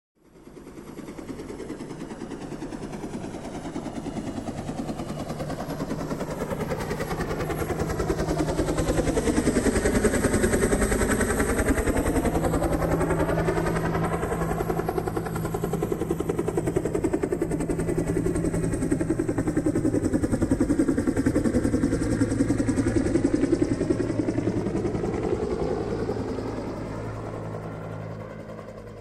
ELICOPTERO HELICOPTER 3D
Ambient sound effects
Elicoptero_Helicopter_3d.mp3